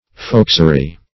Foxery \Fox"e*ry\, n. Behavior like that of a fox; cunning.